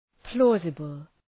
Προφορά
{‘plɔ:zəbəl} (Επίθετο) ● εύλογος ● καπάτσος